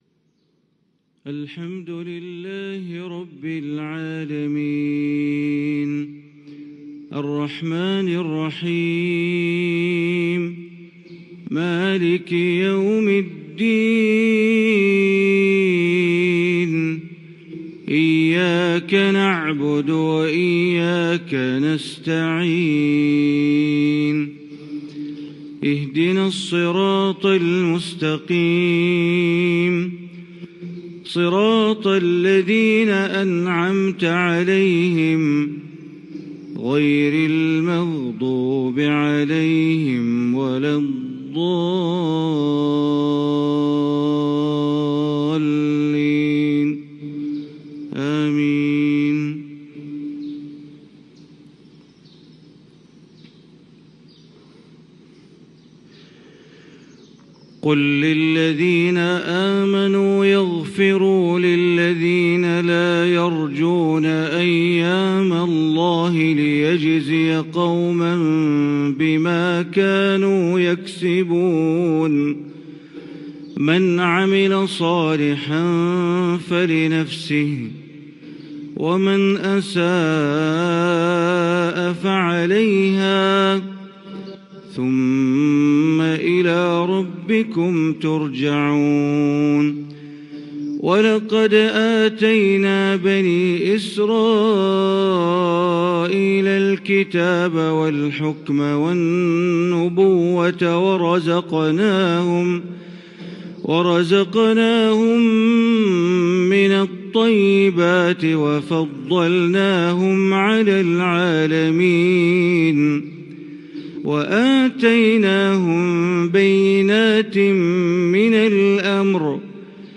فجر 6-4-1442 سورة الجاثية 14-37 > 1442 هـ > الفروض - تلاوات بندر بليلة